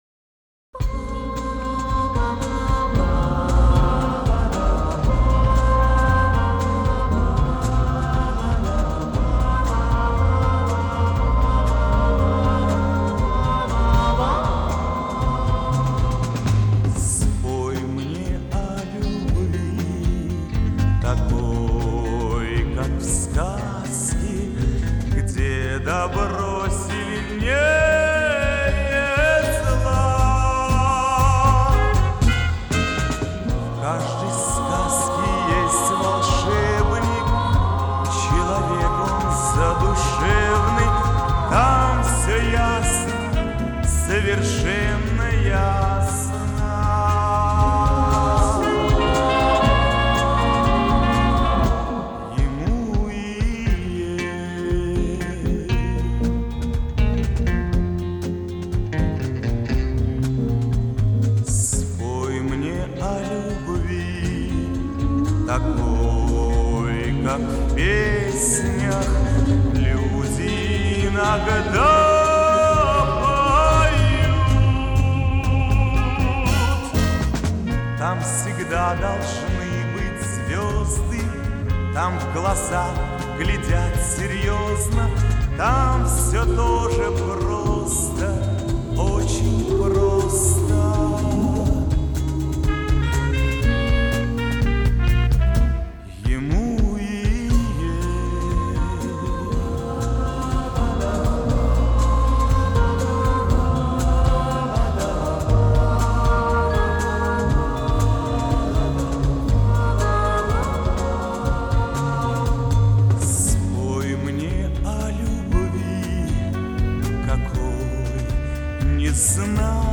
Записи Ленинградского радио.